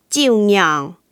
Hong/Hakka_tts